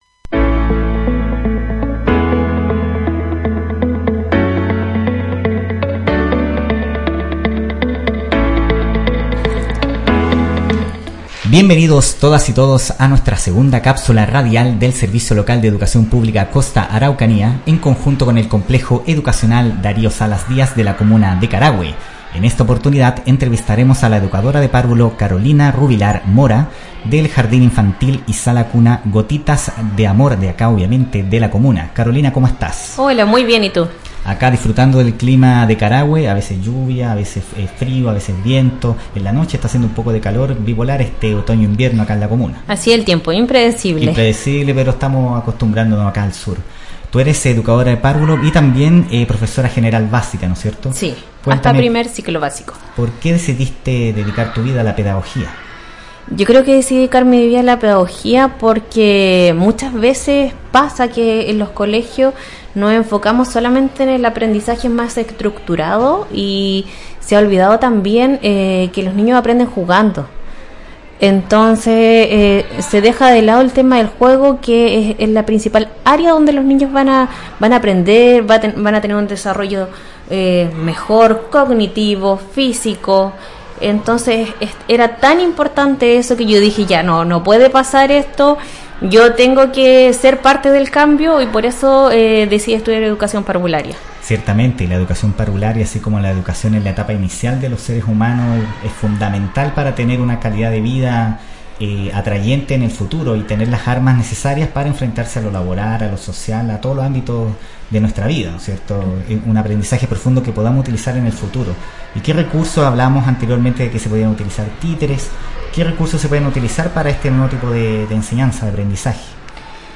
El Servicio Local de Educación Pública Costa Araucanía (SLEPCA) y el Complejo Educacional Darío Salas Díaz de Carahue, se unieron para realizar Cápsulas Radiales en el Locutorio del establecimiento educacional.